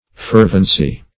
fervency - definition of fervency - synonyms, pronunciation, spelling from Free Dictionary
Fervency \Fer"ven*cy\, n. [Cf. OF. fervence.